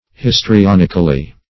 histrionically - definition of histrionically - synonyms, pronunciation, spelling from Free Dictionary
-- His`tri*on"ic*al*ly, adv.